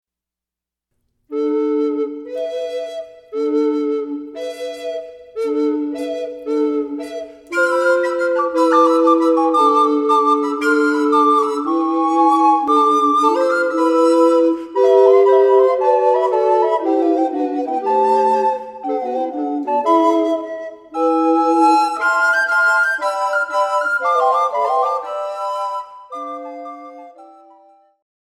Here are two arrangements for SATB based on estampies from the Robertsbridge Codex of c.1360. The medieval sonorities are a refreshing surprise, contrasting well with harmonies more familiar to our modern ears. Parallel fourths, hockets and drones fill the air with a super-early vibe that's a lot of fun to play. A brisk tempo keeps things engaging, but look ahead to the fast patterns in Estampie 2 before settling on an overall tempo.